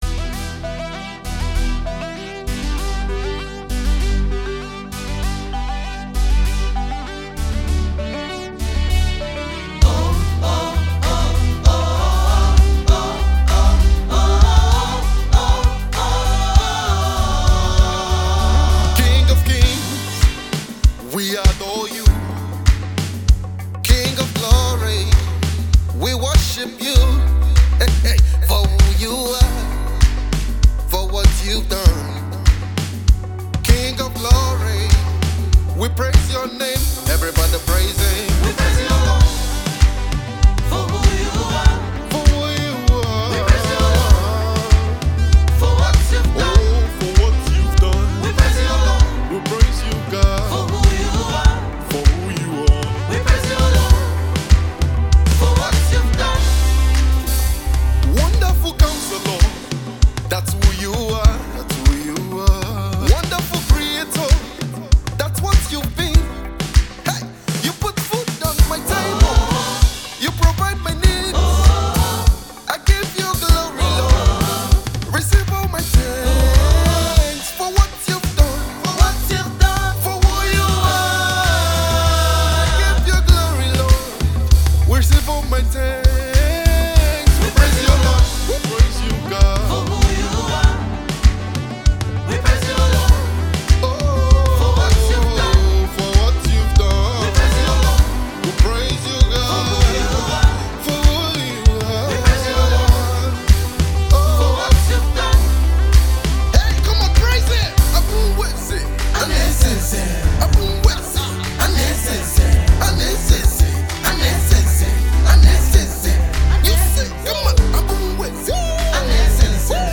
Dubai based Gospel artist
mid-tempo